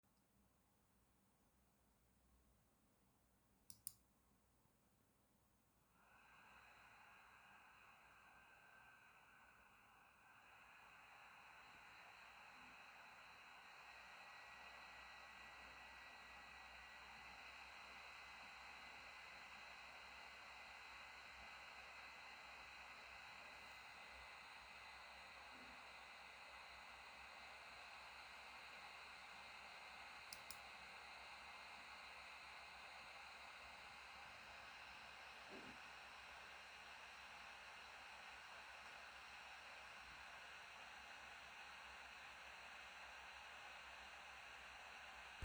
Um die Geräuschkulisse besser zu dokumentieren finden sich nachfolgend drei Tonaufnahmen, die den Flüstermodus, das Standardprofil und den Leistungsmodus abbilden. Die Aufnahme erfolgte mit 40 Zentimetern zur Gehäusefront aus dem Leerlauf in einen CPU-Volllast-Benchmark.
Dadurch ändert sich auch das Geräusch, was mitunter als nervig empfunden werden kann.